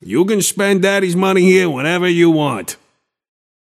Shopkeeper voice line - You can spend daddy's money here whenever you want.
Shopkeeper_hotdog_t4_pocket_01.mp3